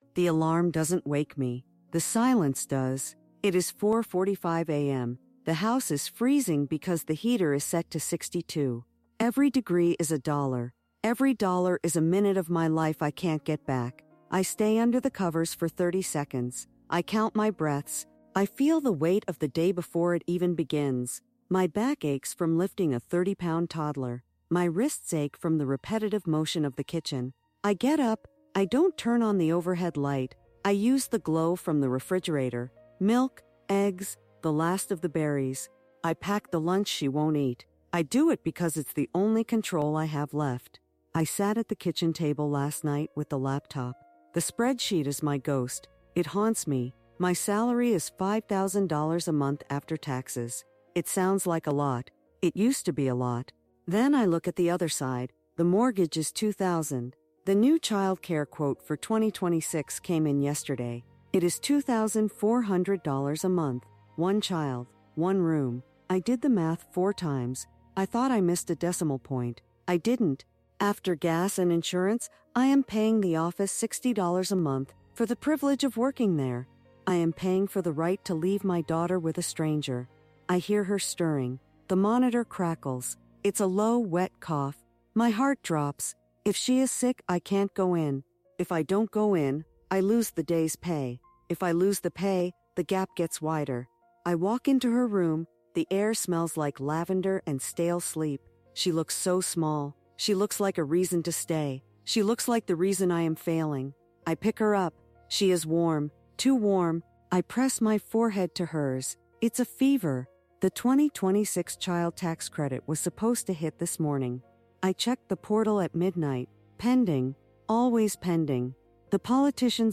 This first-person storytelling experience captures the "Caregiver's Debt"—the silent toll of rising inflation and stagnant social support that is redefining motherhood and career ambition in the modern era.